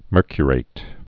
(mûrkyə-rāt)